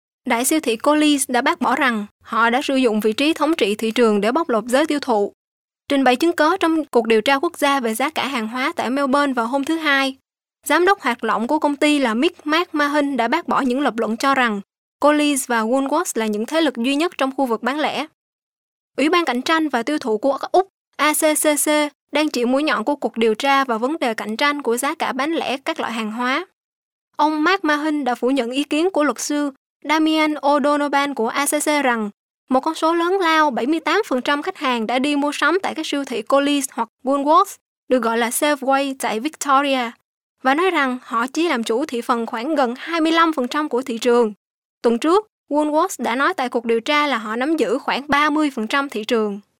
Kein Dialekt
Sprechprobe: Werbung (Muttersprache):